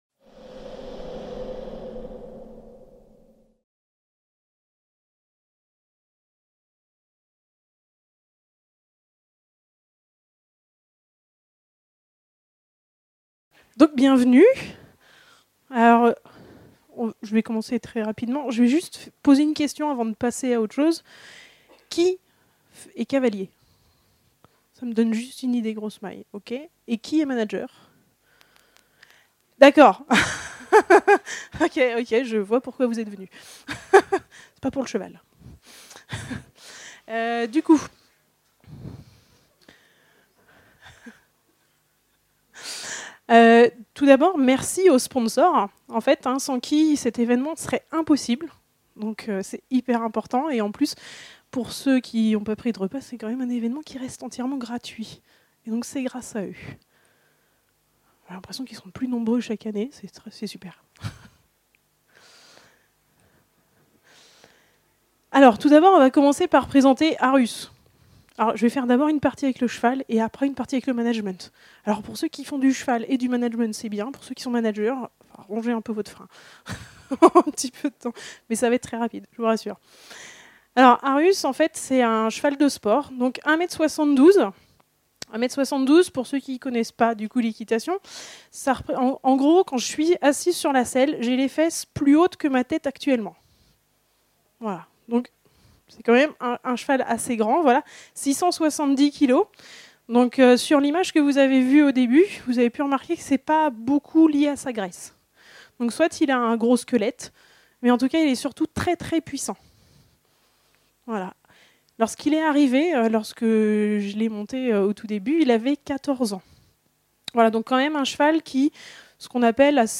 Type : Conférence Thématique